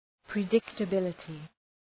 Προφορά
{prı,dıktə’bılətı}